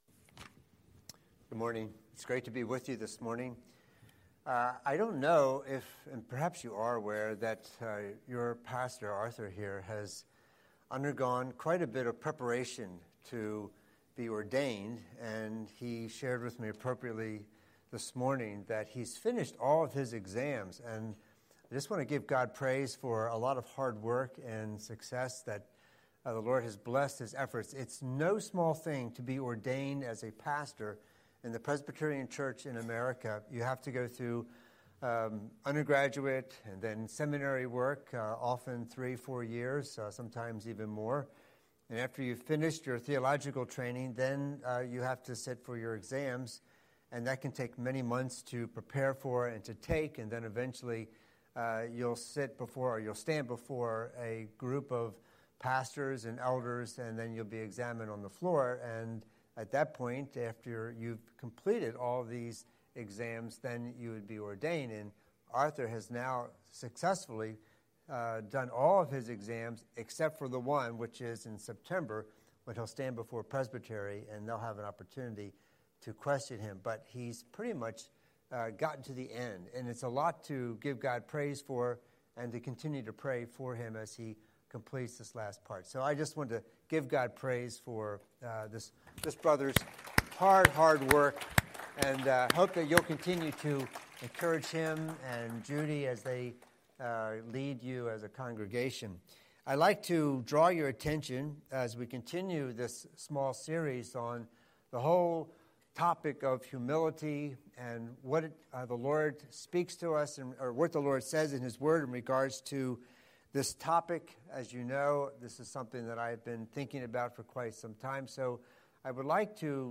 Scripture: Psalm 131:1-3 Series: Sunday Sermon